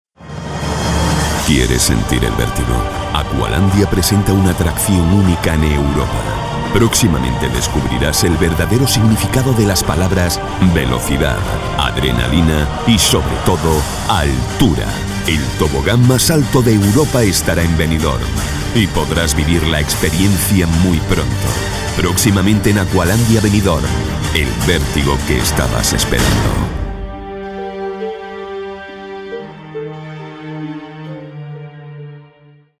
Voz directa para transmitir emociones
Sprechprobe: Werbung (Muttersprache):
Direct voice to convey emotions